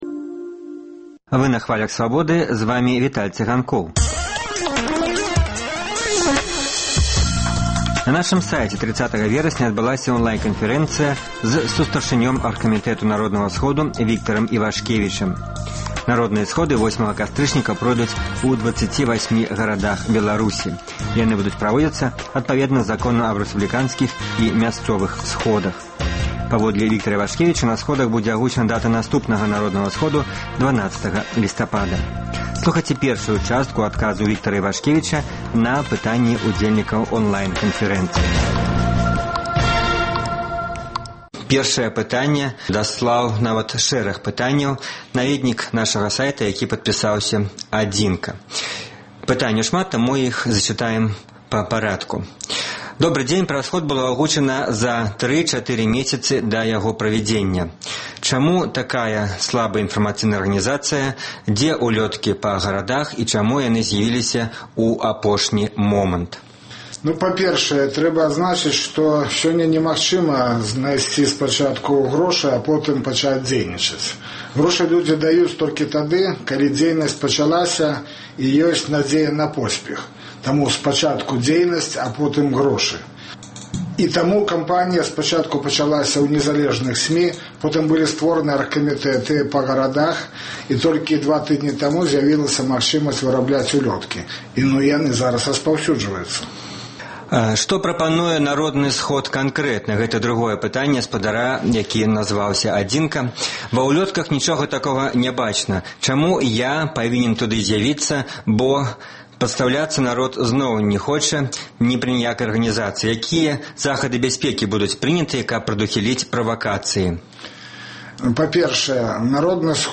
Радыёварыянт онлайн-канфэрэнцыі